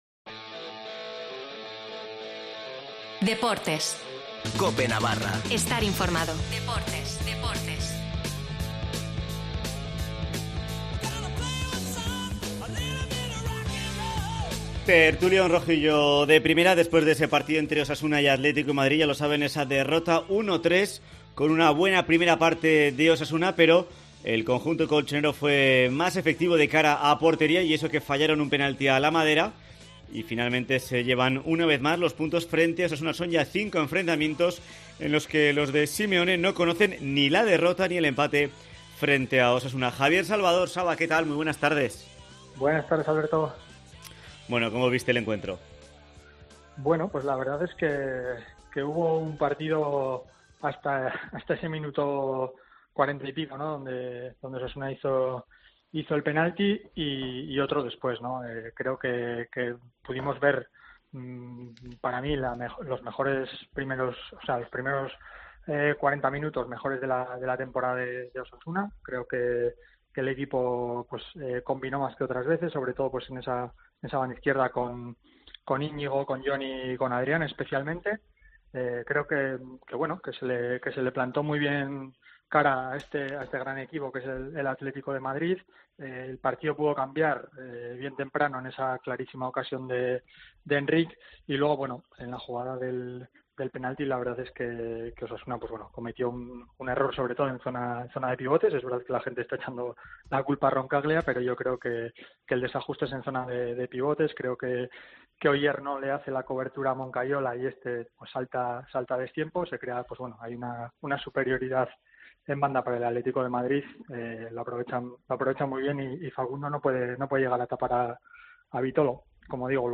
Tertulión rojillo de Primera tras el Osasuna-Atlético con derrota rojilla